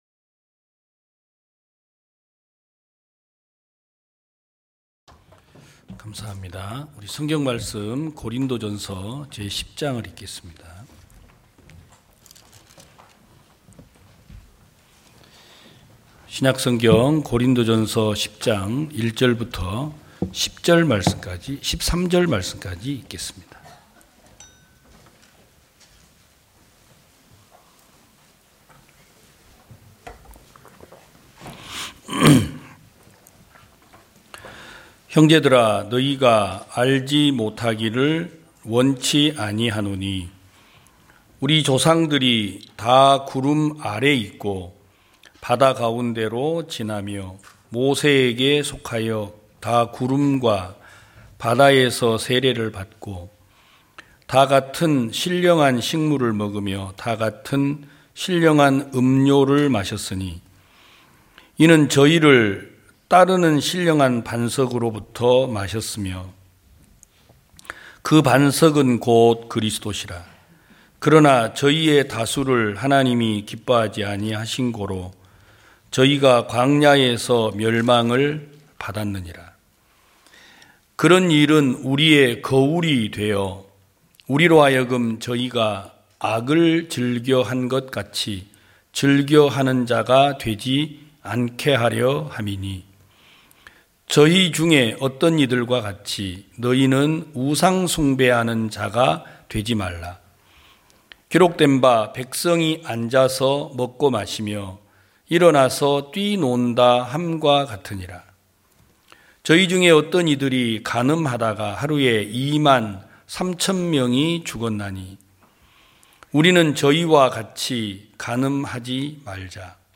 2022년 11월 27일 기쁜소식부산대연교회 주일오전예배
성도들이 모두 교회에 모여 말씀을 듣는 주일 예배의 설교는, 한 주간 우리 마음을 채웠던 생각을 내려두고 하나님의 말씀으로 가득 채우는 시간입니다.